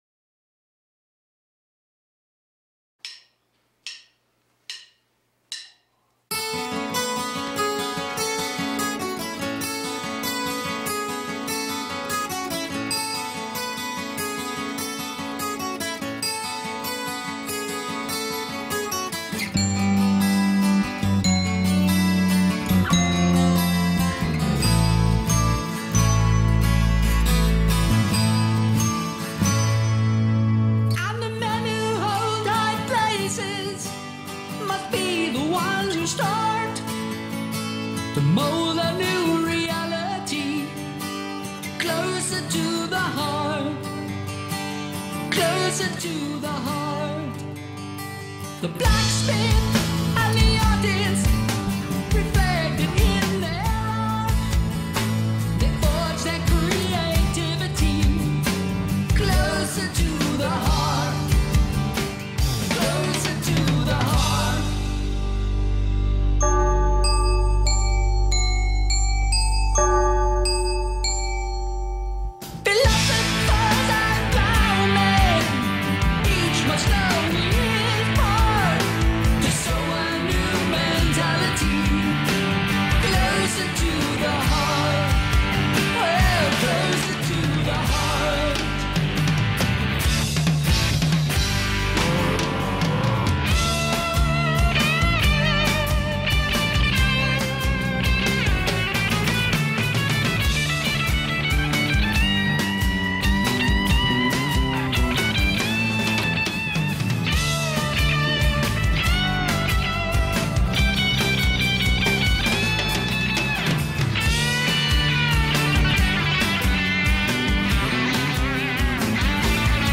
پراگرسیو راک